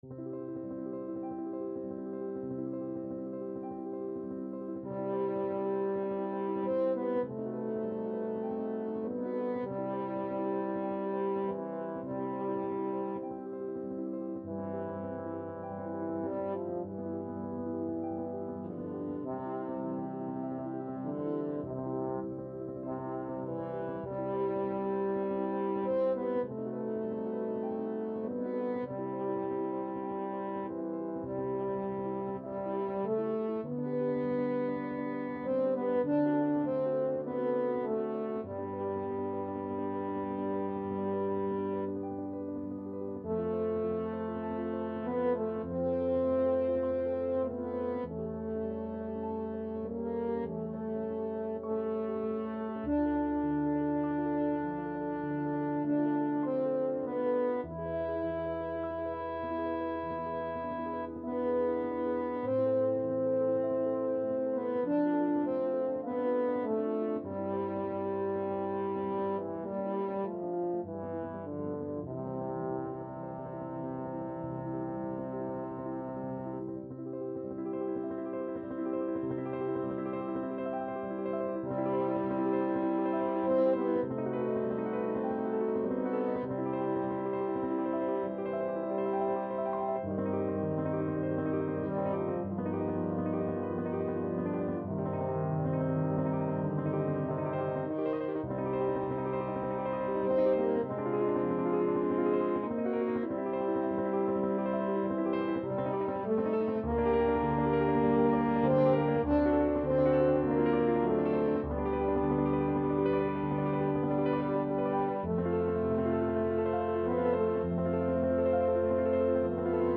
Play (or use space bar on your keyboard) Pause Music Playalong - Piano Accompaniment Playalong Band Accompaniment not yet available reset tempo print settings full screen
French Horn
C major (Sounding Pitch) G major (French Horn in F) (View more C major Music for French Horn )
Sanft bewegt
Classical (View more Classical French Horn Music)